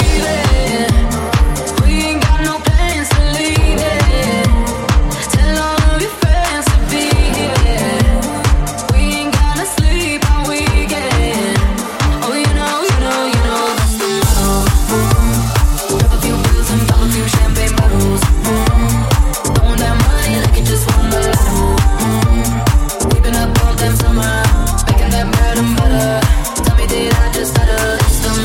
Genere: house,deep,edm,remix,hit